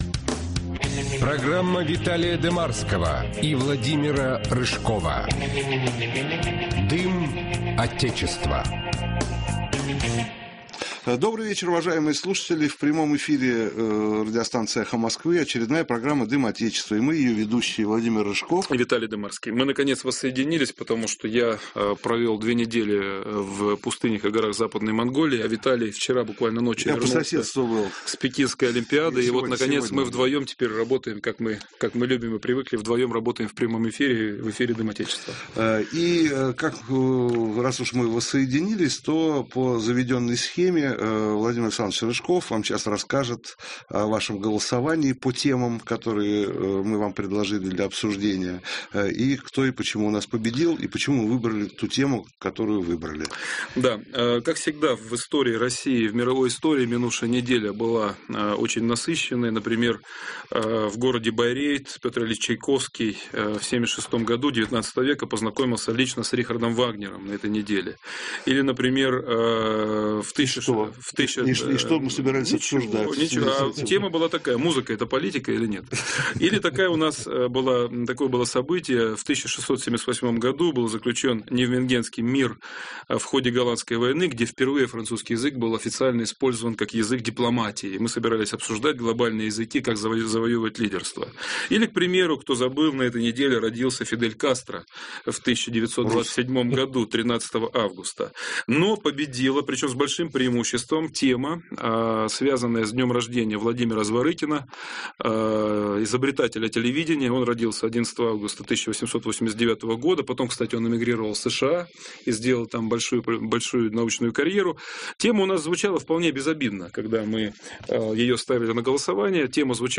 В.РЫЖКОВ: И, наконец, как мы любим и привыкли, мы работаем в прямом эфире.